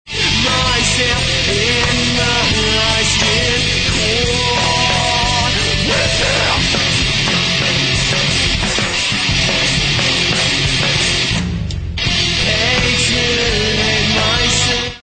neo métal